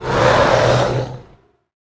gnoll_commander_attack_2.wav